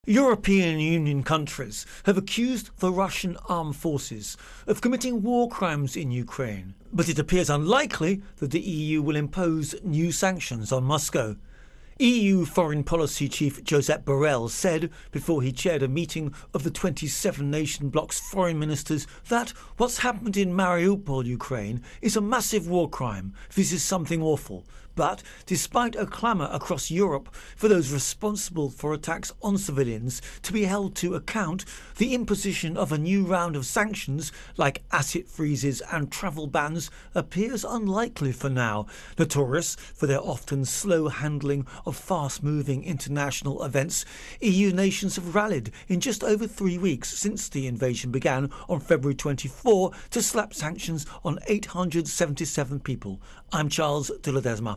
Russia-Ukraine-War-Europe Intro and Voicer